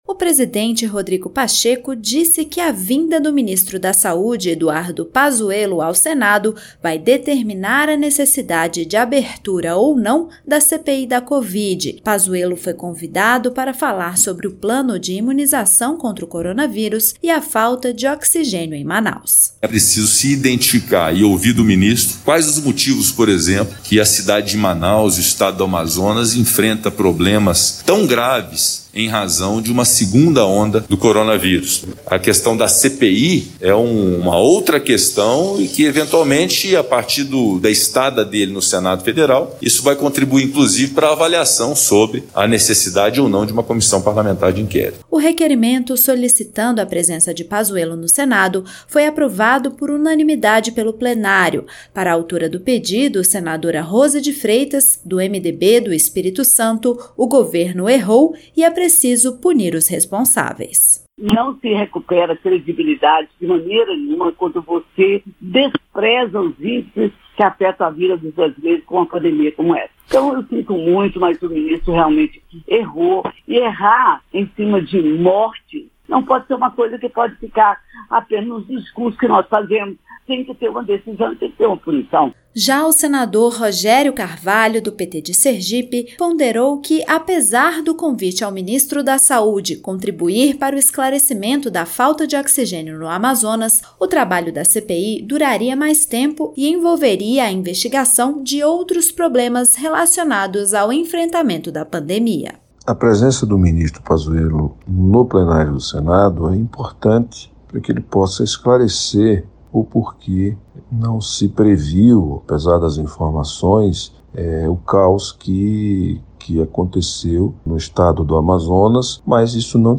O senador Rogério Carvalho (PT-SE) ponderou que os trabalhos da CPI durariam mais tempo e não se restringiriam a esses temas.